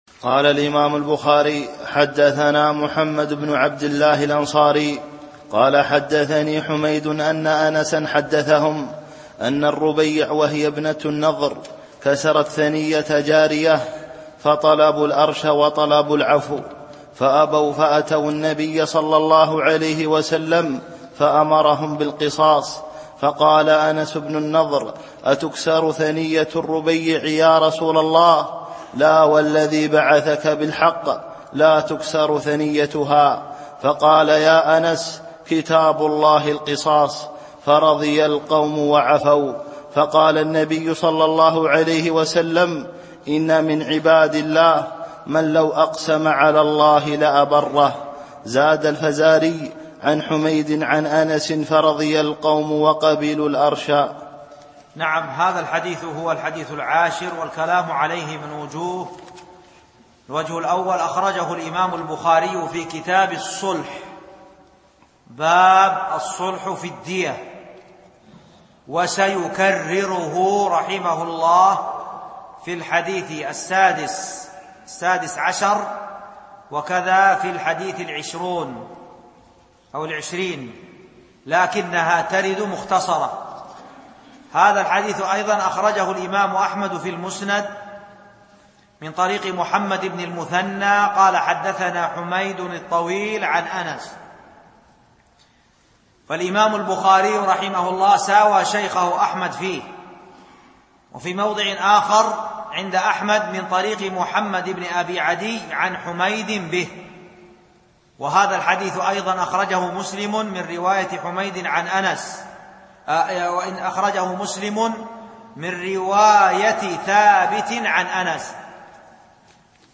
الألبوم: شبكة بينونة للعلوم الشرعية المدة: 14:17 دقائق (3.31 م.بايت) التنسيق: MP3 Mono 22kHz 32Kbps (VBR)